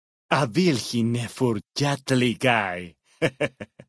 Dead Horses pidgin audio samples Du kannst diese Datei nicht überschreiben.